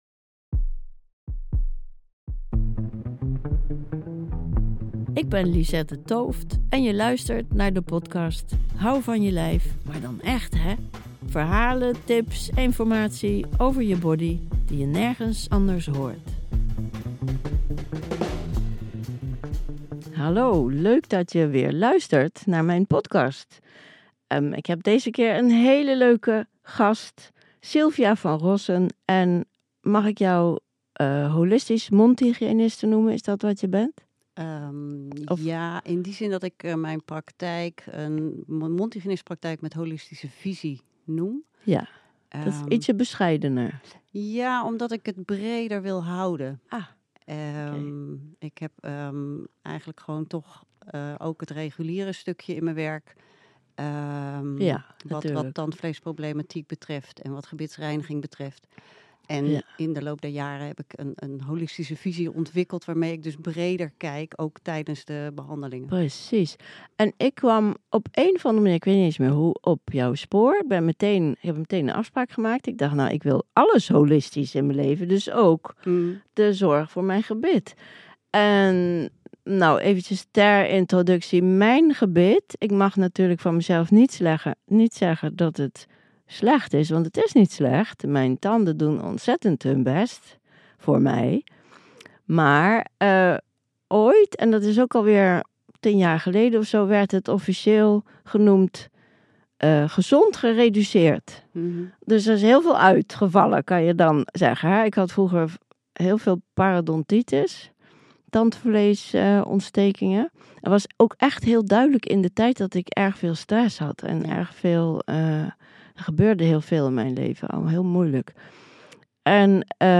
Deze aflevering is opgenomen bij Sound Art Studio's in Rotterdam West